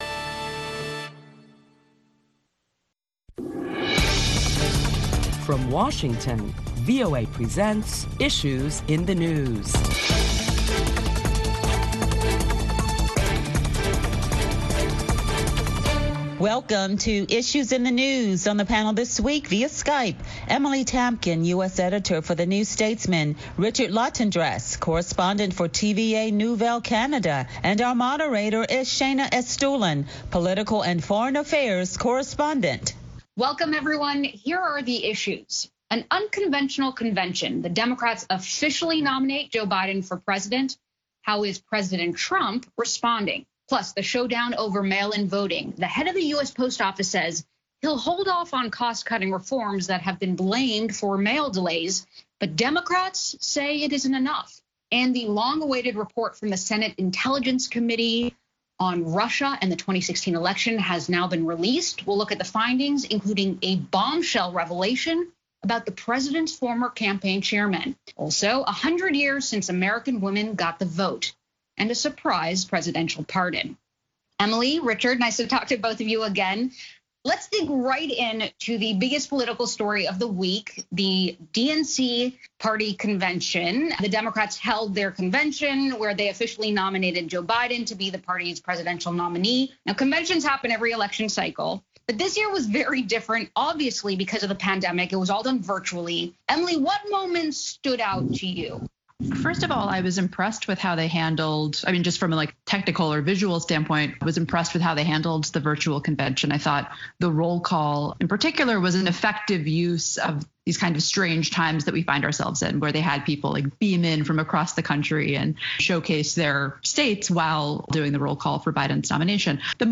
Join a panel of prominent Washington journalists as they deliberate the latest top stories of the week which include the number of coronavirus cases continue to rise around the globe.